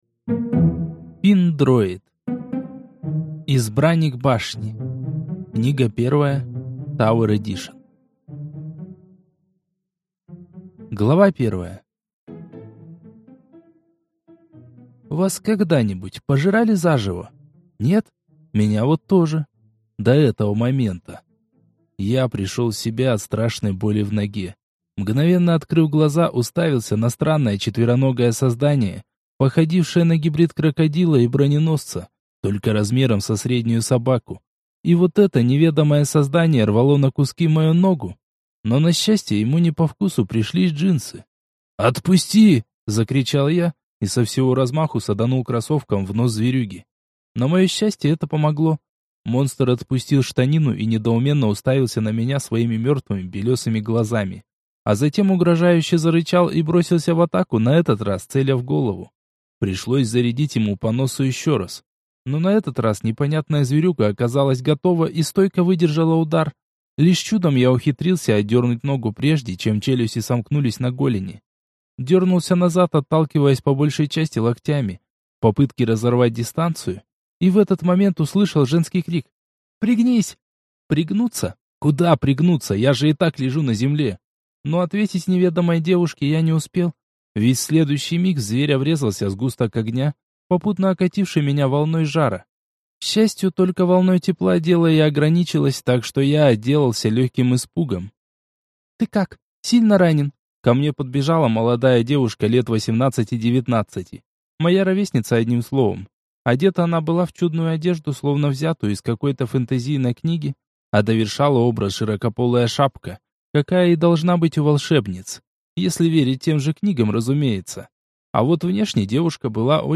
Аудиокнига Избранник Башни. Книга 1. Tower Edition | Библиотека аудиокниг